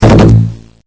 リールが回転した瞬間に聞こえる2種類の予兆サウンド！
スイカ予兆音
「ドコドン」と鳴って、スイカがはずれたら・・・